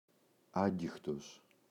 άγγιχτος [‘aŋgixtos]